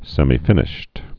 (sĕmē-fĭnĭsht, sĕmī-)